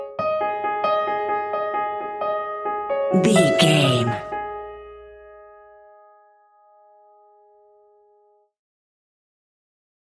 Quick Piano Scene Change Link.
Aeolian/Minor
tension
ominous
dark
haunting
eerie
short stinger
short music instrumental
horror scene change music